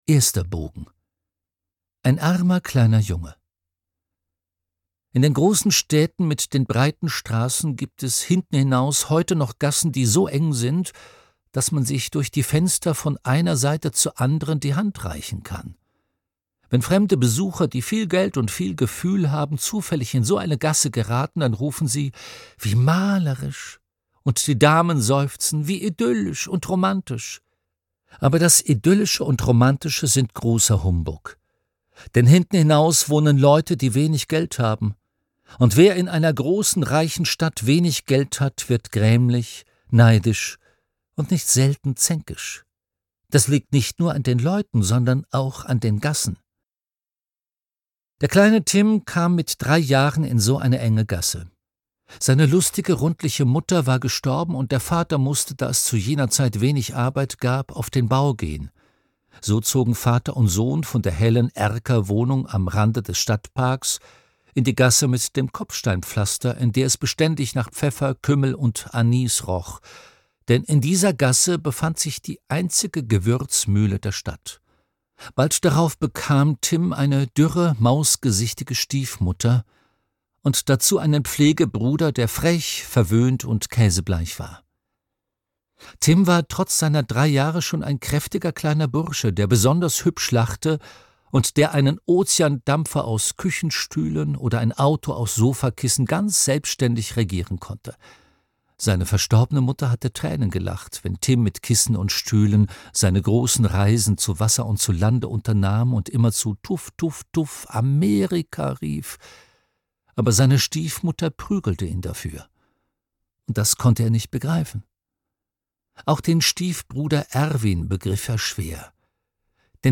Ungekürzte Lesung mit Rufus Beck (1 mp3-CD)
Rufus Beck (Sprecher)